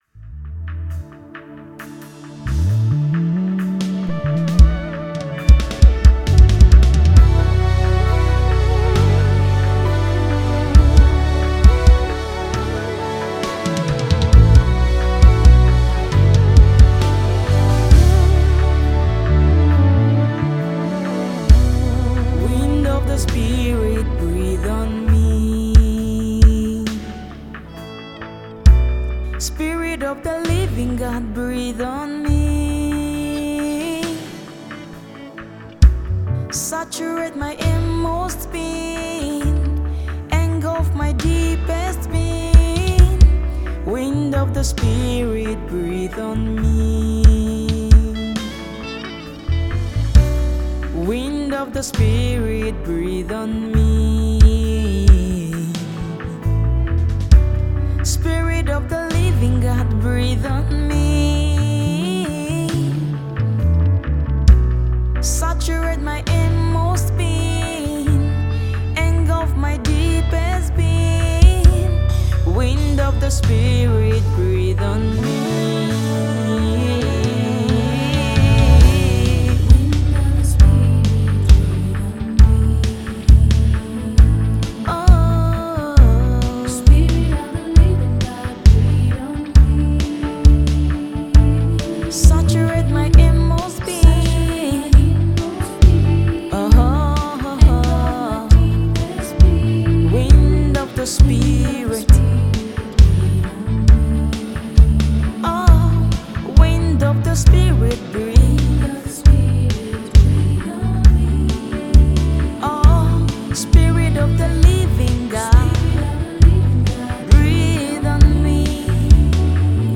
Gospel Music
spirited and uplifting songs